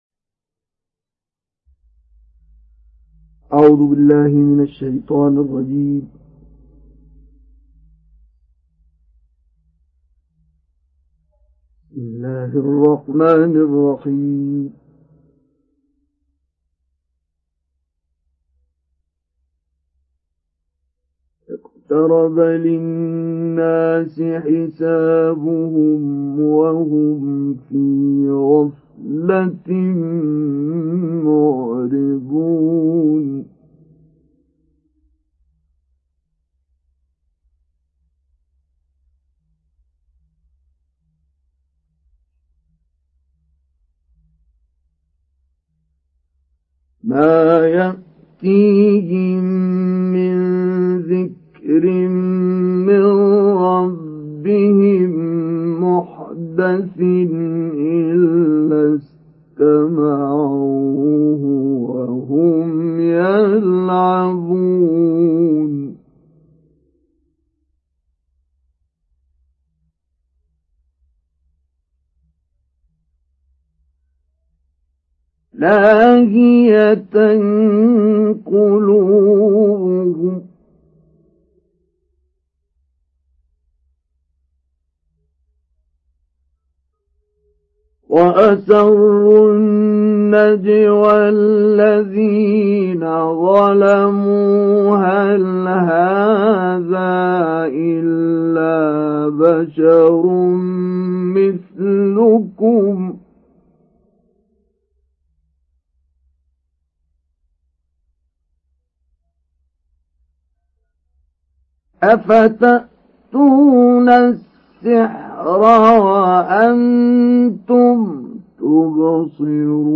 Surat Al Anbiya Download mp3 Mustafa Ismail Mujawwad Riwayat Hafs dari Asim, Download Quran dan mendengarkan mp3 tautan langsung penuh
Download Surat Al Anbiya Mustafa Ismail Mujawwad